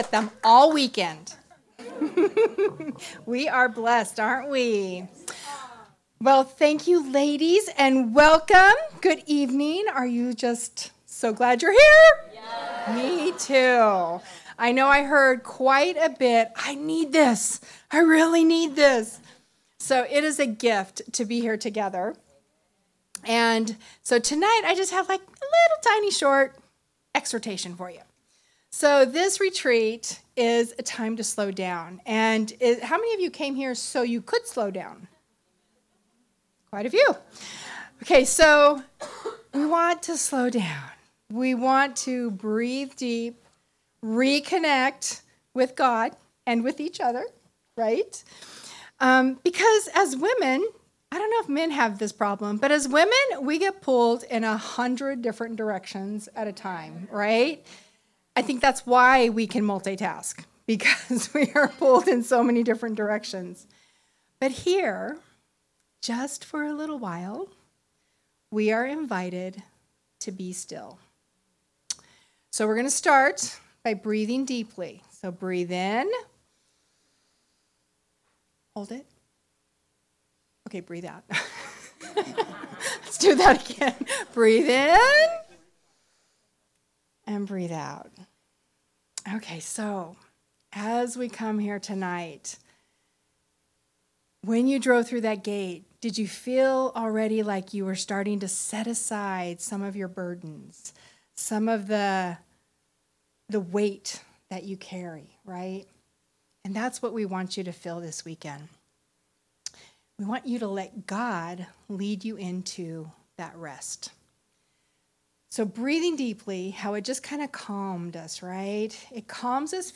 Sermons | Calvary Chapel Pahrump Valley